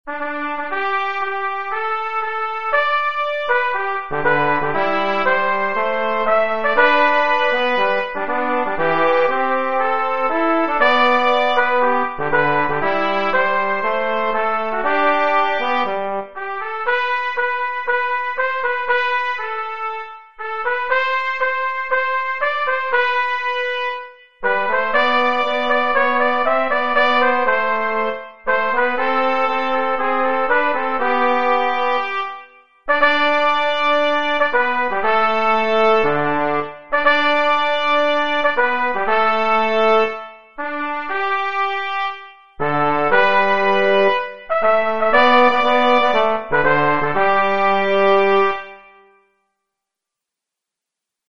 Polyphonic Duo
camarsr-duo.mp3